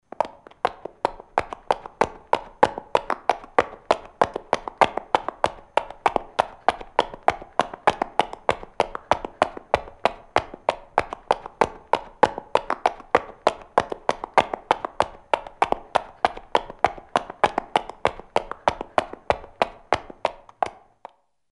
小跑着的高跟鞋2.mp3
通用动作/01人物/01移动状态/高跟鞋/小跑着的高跟鞋2.mp3
• 声道 立體聲 (2ch)